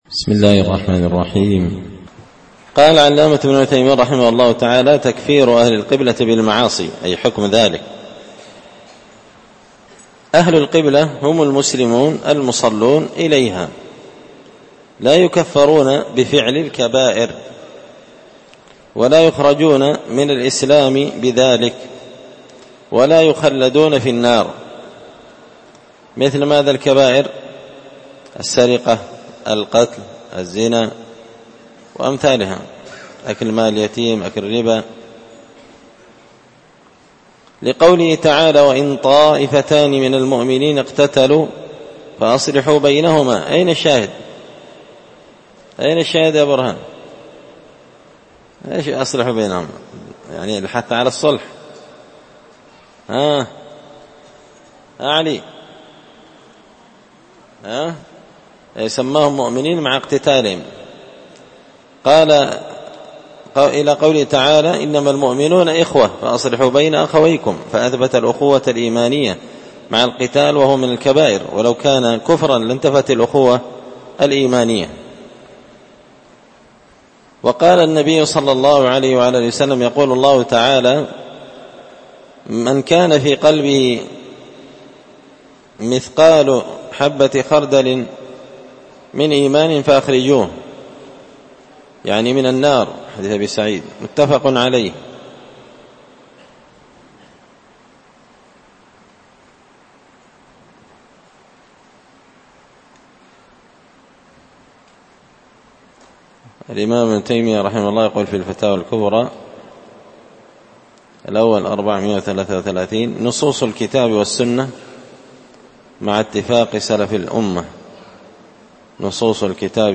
شرح لمعة الاعتقاد ـ الدرس 47
دار الحديث بمسجد الفرقان ـ قشن ـ المهرة ـ اليمن